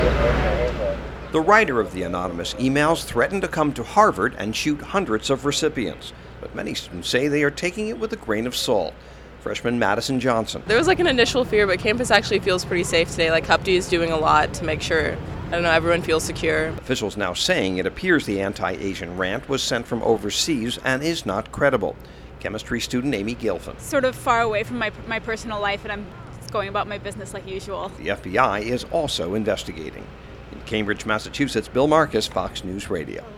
REPORTS FROM CAMBRIDGE, MASSACHUSETTS: